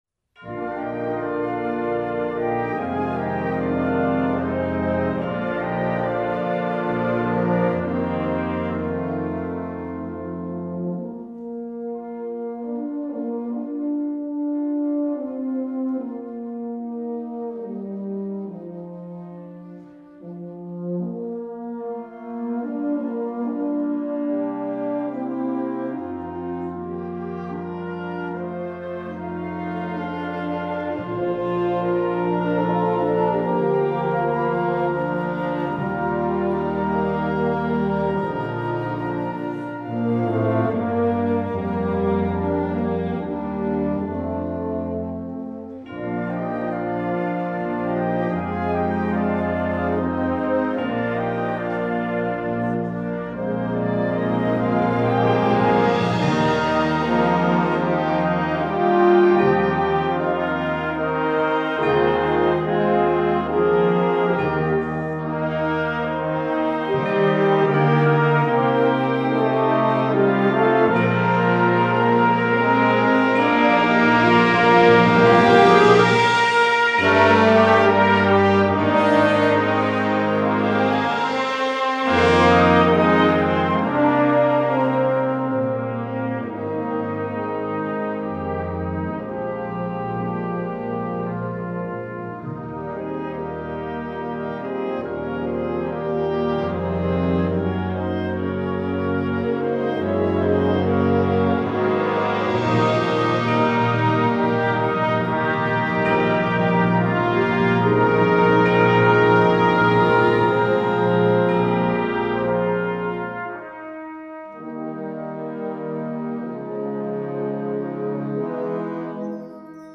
instructional, american, children